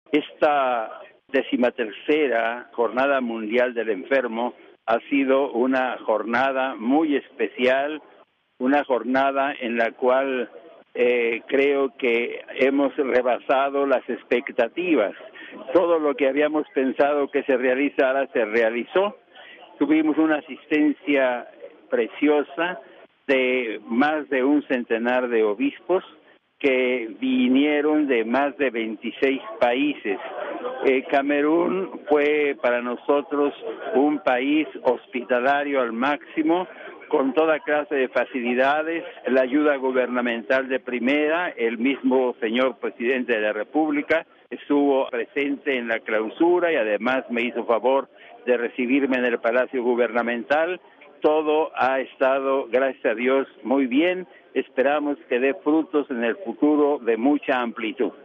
A propósito de esta Jornada, escuchemos desde Camerún al Enviado Especial del Papa, el Cardenal Javier Lozano Barragán, Presidente del Pontificio Consejo para la Pastoral de la Salud.RealAudio